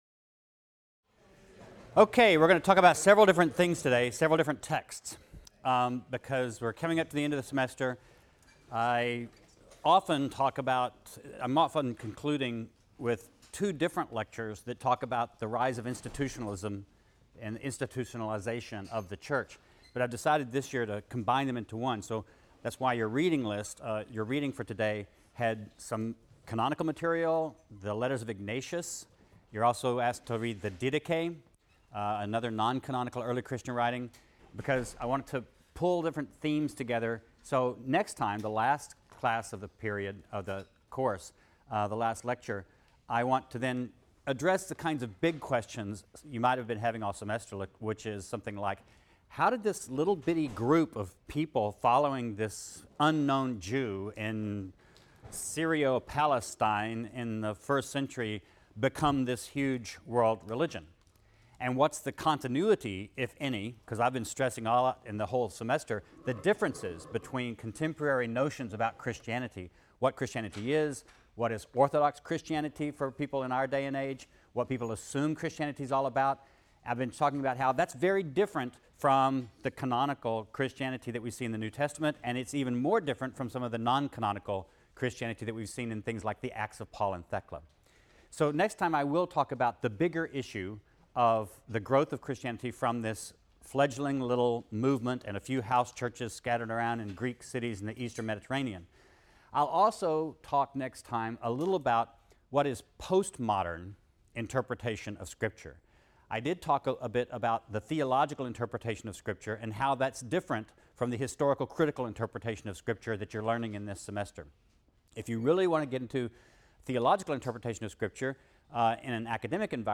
RLST 152 - Lecture 25 - Ecclesiastical Institutions: Unity, Martyrs, and Bishops | Open Yale Courses